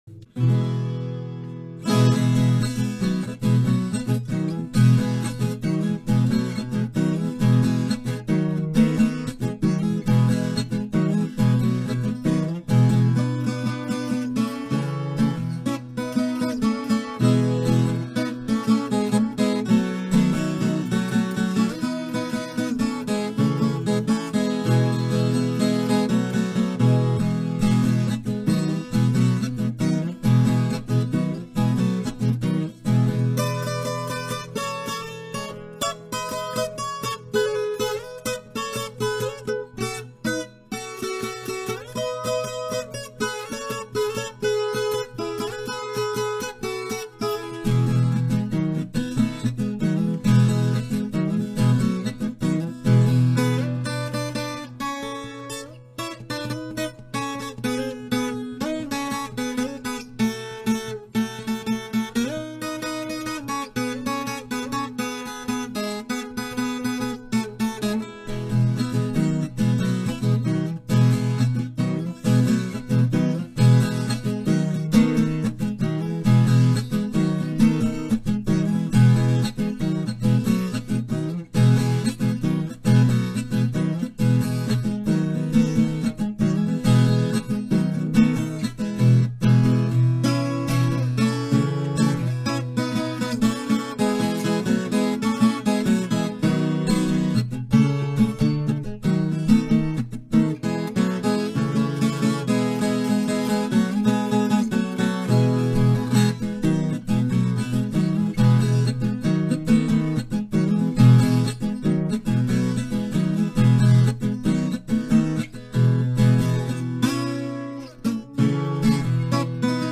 uma música